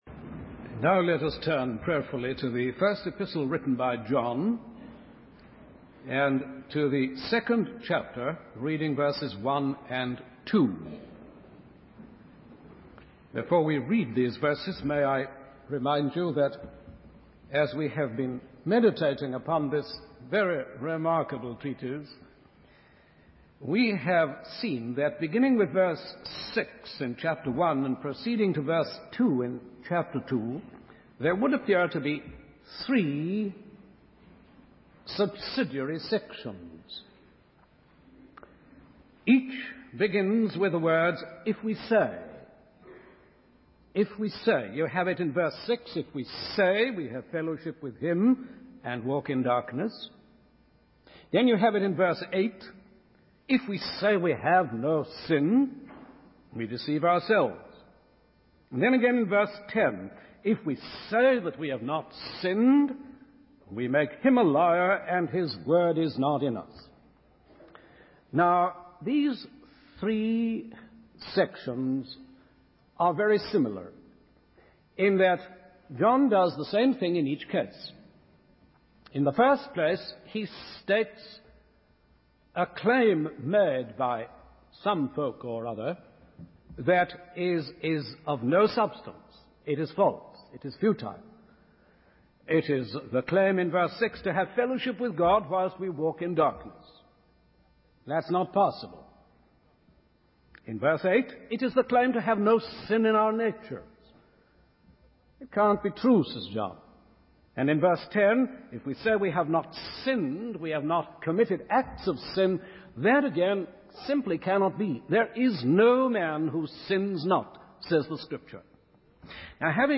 In this sermon, the preacher focuses on two main sections: the solemn predicament of a Christian who has fallen into sin and the sufficient provision for forgiveness.